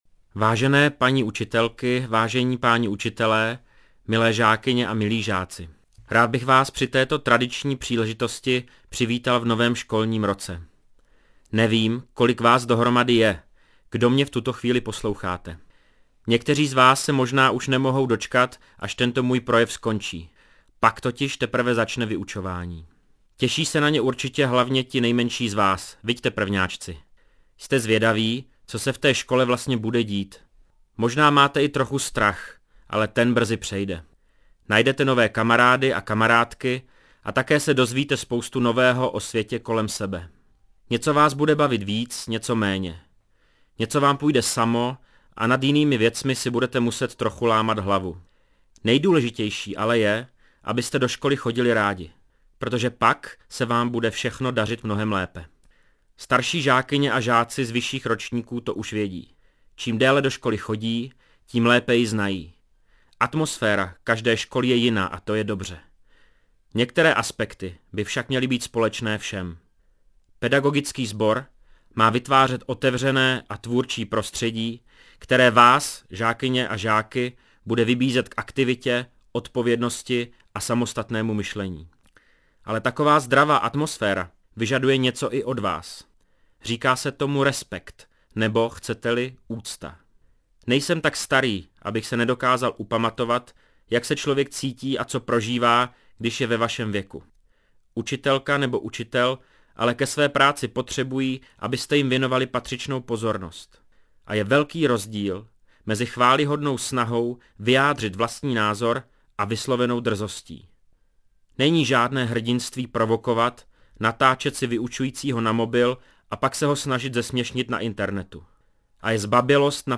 projev_zari_2008.mp3